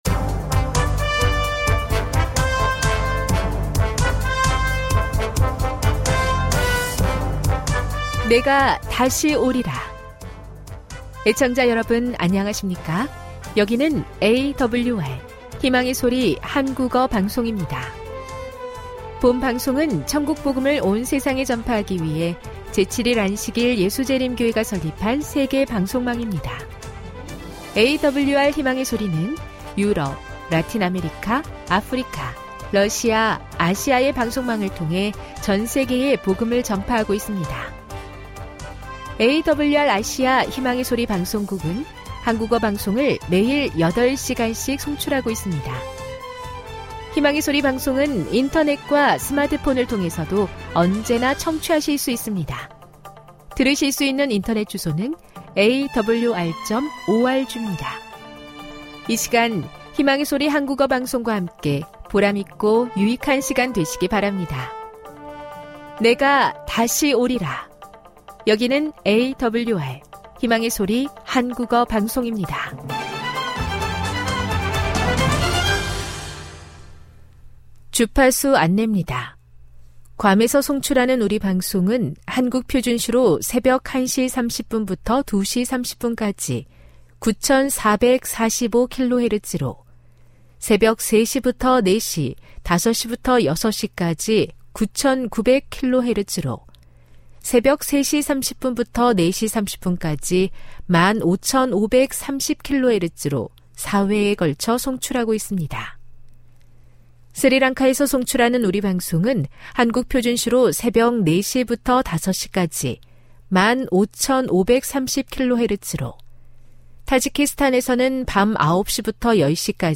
1 설교, 말씀묵상 58:25